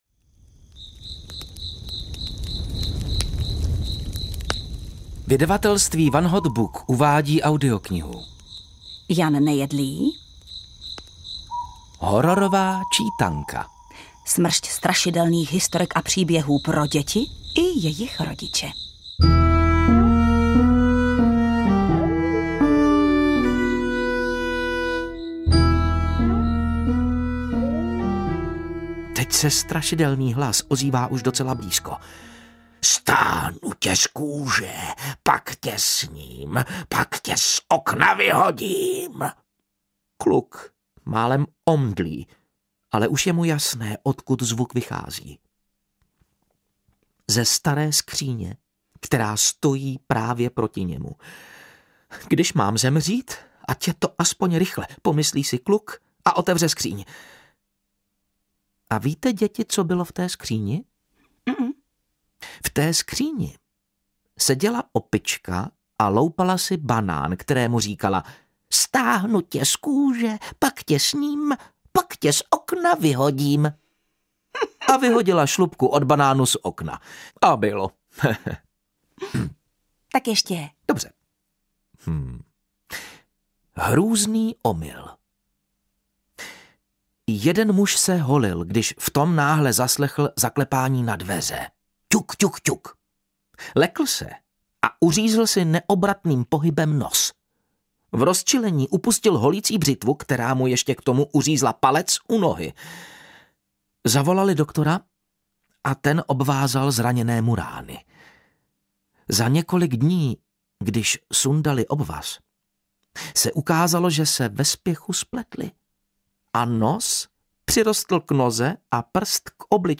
Hororová čítanka audiokniha
Ukázka z knihy
• InterpretJana Stryková, Matouš Ruml